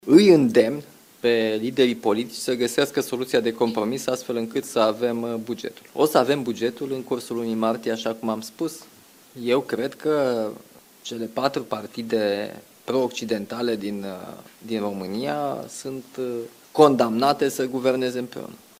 De la Bruxelles, unde participă la Consiliul European, președintele Nicușor Dan a cerut PNL, PSD, USR și UDMR să ajungă la un compromis.